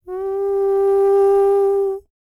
Index of /90_sSampleCDs/ILIO - Vocal Planet VOL-3 - Jazz & FX/Partition D/6 HUMAN TRPT